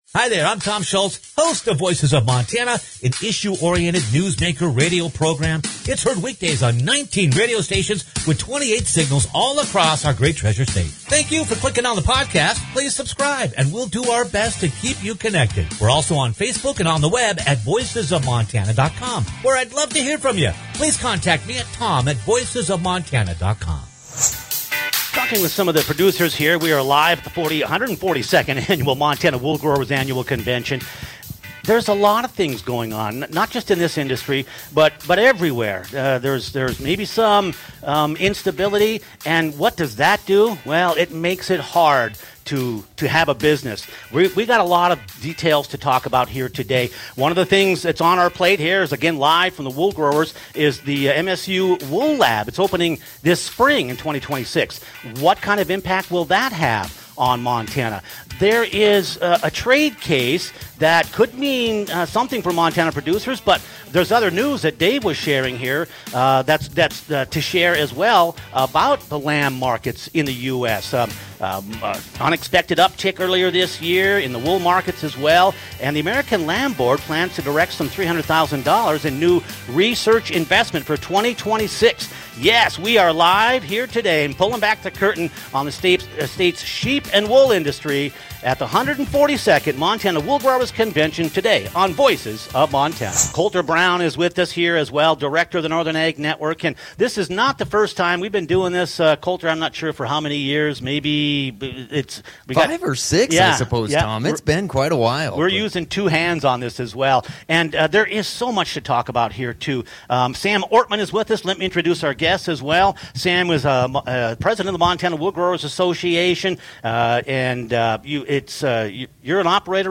LIVE from the 142nd Annual Wool Growers Convention - Voices of Montana
live-from-the-142nd-annual-wool-growers-convention.mp3